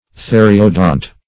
Search Result for " theriodont" : The Collaborative International Dictionary of English v.0.48: Theriodont \The"ri*o*dont\, n. (Paleon.)
theriodont.mp3